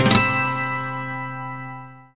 Index of /90_sSampleCDs/Roland LCDP02 Guitar and Bass/GTR_GTR FX/GTR_Gtr Hits 1